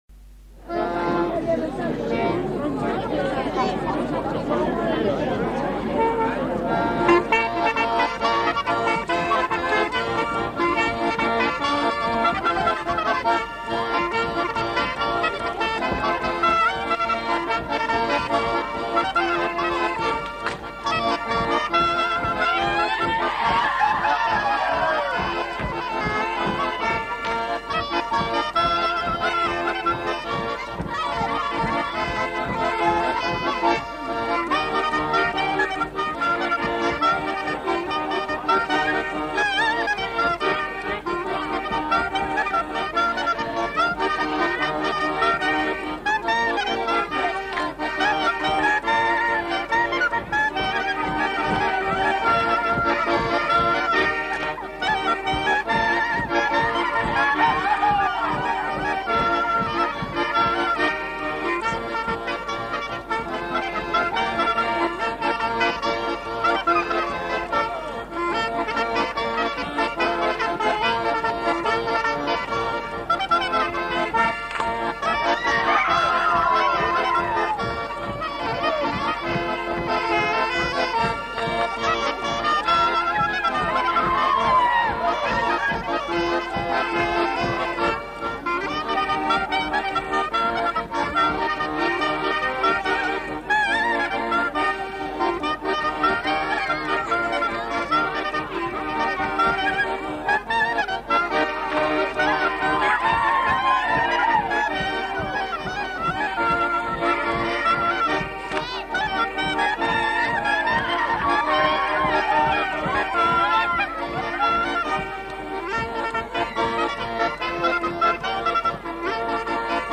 Instrumental. Accordéon chromatique, clarinette. Val Vermenagna
Aire culturelle : Val Vermenagna
Lieu : Limone
Genre : morceau instrumental
Instrument de musique : clarinette ; accordéon chromatique
Notes consultables : Les deux musiciens ne sont pas identifiés.